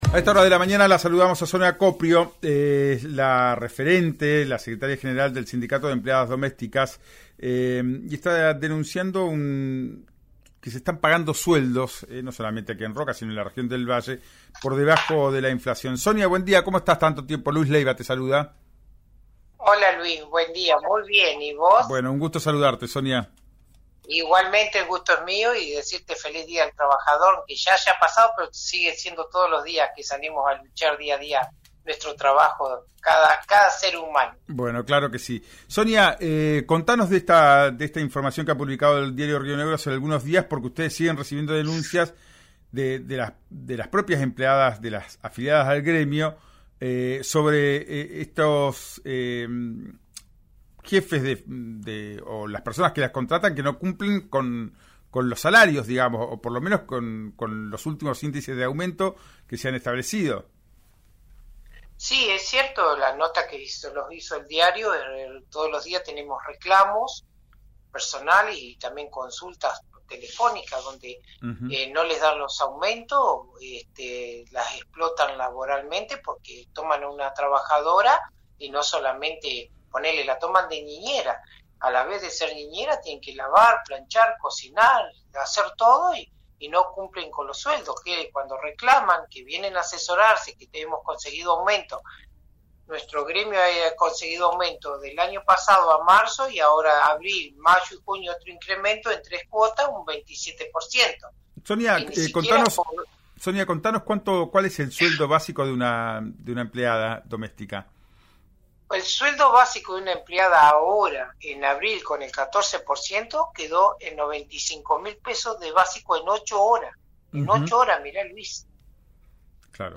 En diálogo con «Ya es tiempo» de RÍO NEGRO RADIO